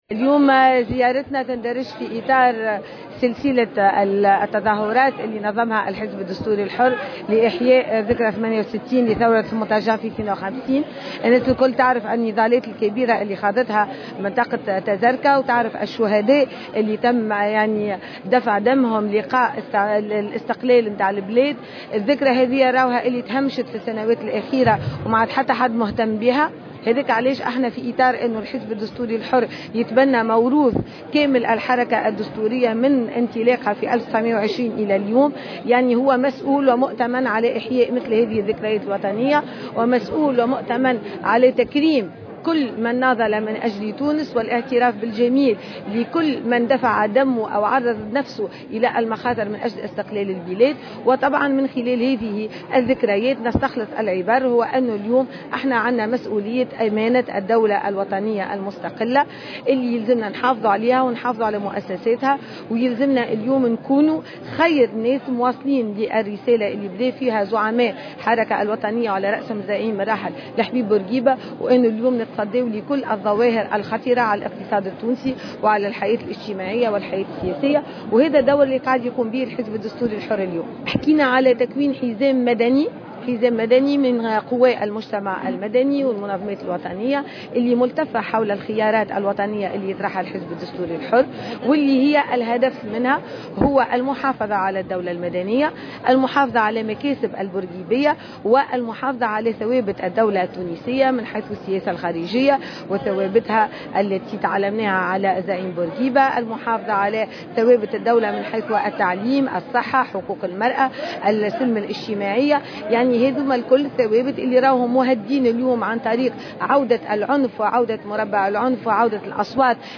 وأكدت مساء الخميس، على هامش تنظيم اجتماع شعبي بمدينة تازركة من ولاية نابل بمناسبة إحياء الذكرى 68 لأحداث 29 جانفي 1952 بتازركة، أن الحزب الدستوري سيناضل من أجل كشف الحقائق وملفات الفساد وإيقاف نزيف الدمار والخراب الذي تعيشه الدولة، على حد تقديرها.